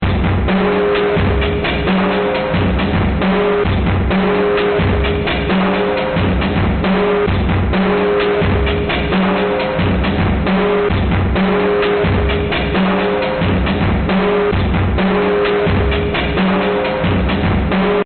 来自Proteus F X的扭曲的鼓声样本 " klong 01
描述：来自Proteus FX的声音样本通过Boss GX700进行扭曲
Tag: 节拍 扭曲 变形 FX 变形杆菌 样品 垃圾桶